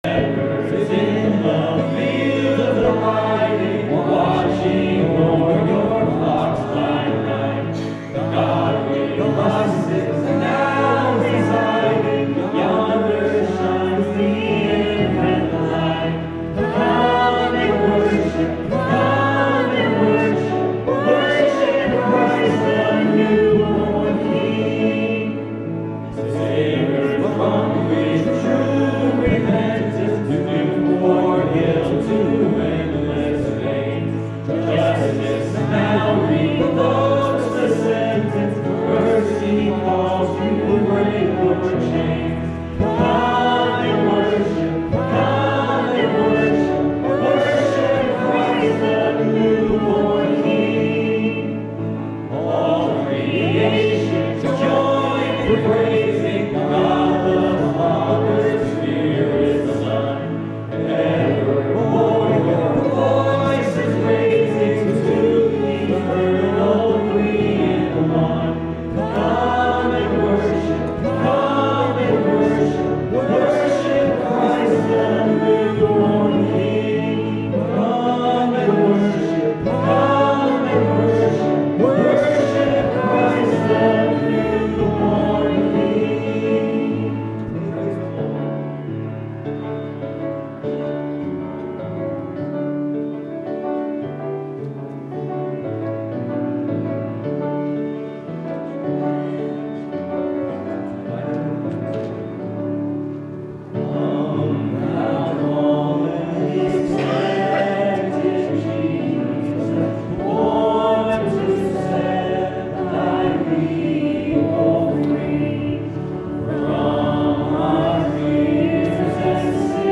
Christmas Eve Candle Light Service